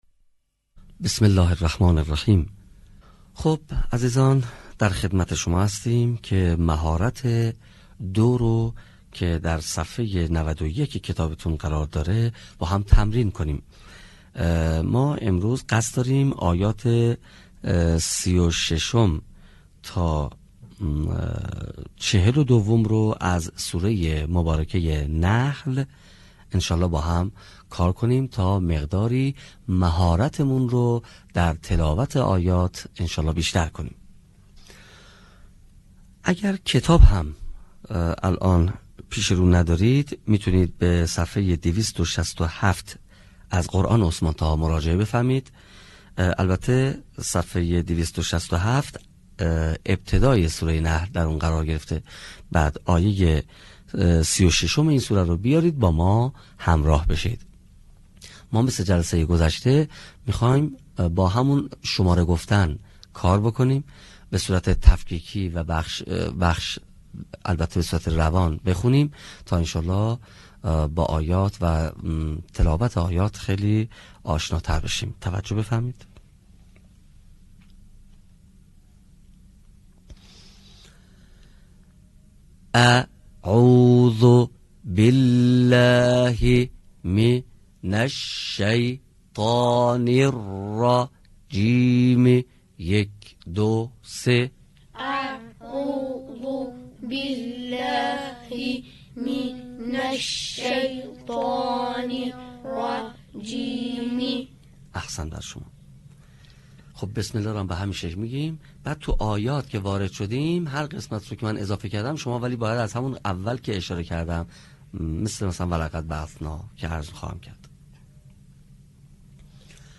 صوت | مهارت خواندنِ آیات ۳۶ تا ۴۲ سوره مبارکه نحل
به همین منظور مجموعه آموزشی شنیداری (صوتی) قرآنی را گردآوری و برای علاقه‌مندان بازنشر می‌کند.